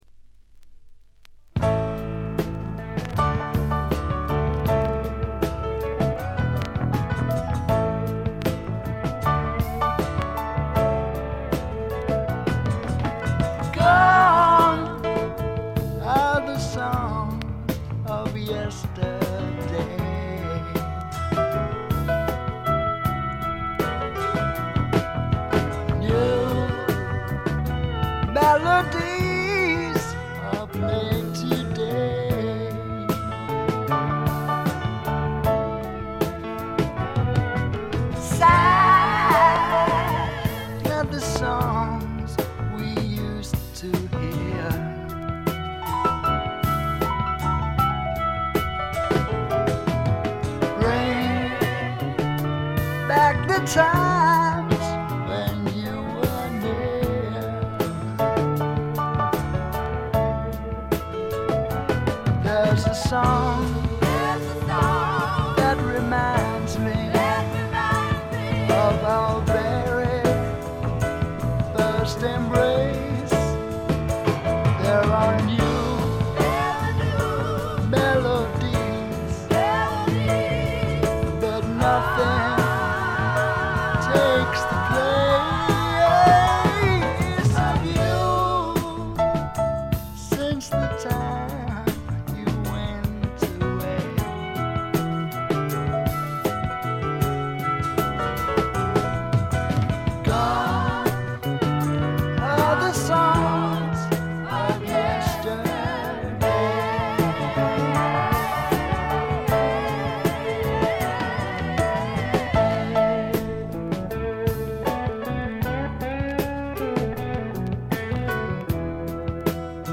搾り出すような激渋のヴォーカルがスワンプ・サウンドにばっちりはまってたまりません。
試聴曲は現品からの取り込み音源です。